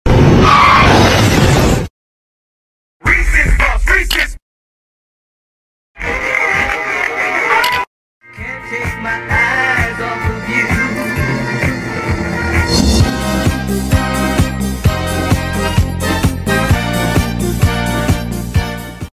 {⚠ Car Crash sound} Death’s sound effects free download